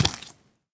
Minecraft Version Minecraft Version snapshot Latest Release | Latest Snapshot snapshot / assets / minecraft / sounds / mob / zombie / step4.ogg Compare With Compare With Latest Release | Latest Snapshot
step4.ogg